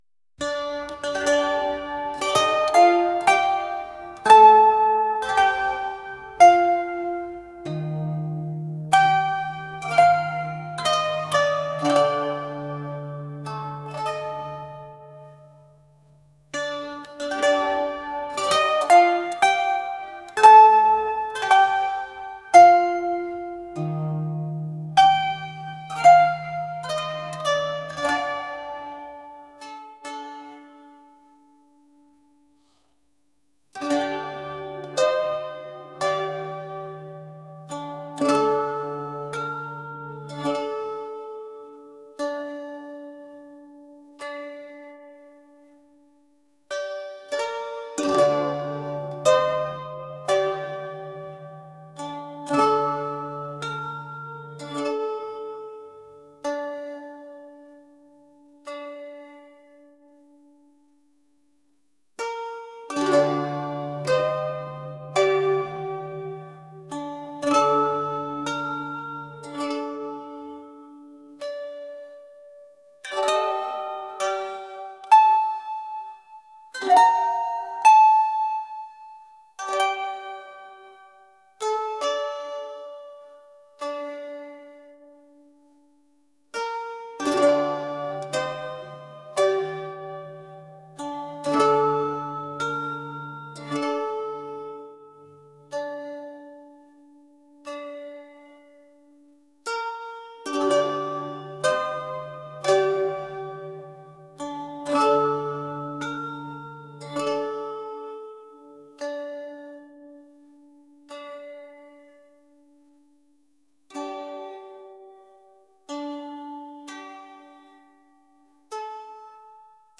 folk | ethereal | world